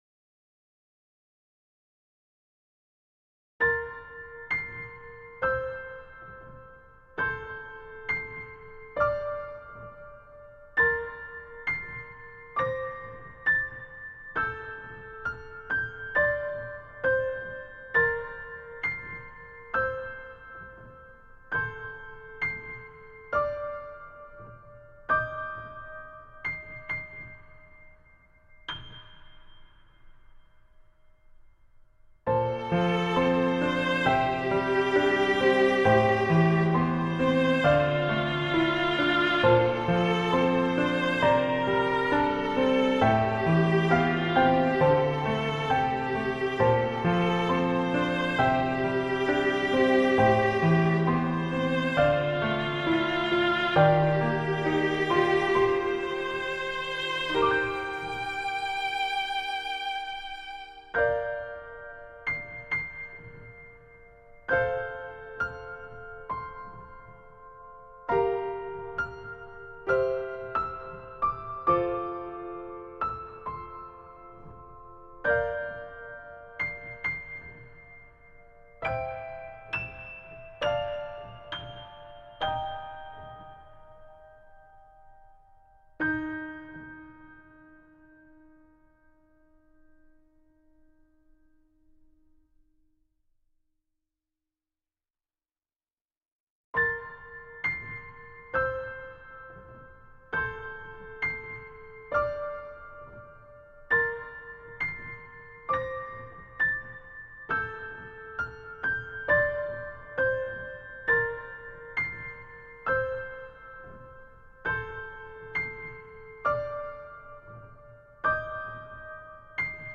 優しい音がする
ピアノ＋弦は好きだけれど、ワンパターンになりがちな・・・